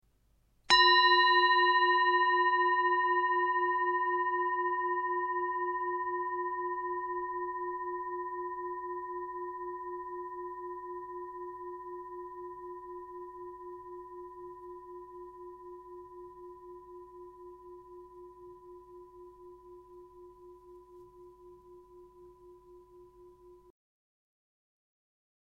Indische Bengalen Klangschale - HERZSCHALE
Gewicht: 619 g
Durchmesser: 14,5 cm